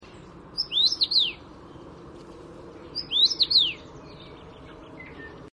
Punavarpunen
Laulu on kirkas vihellys.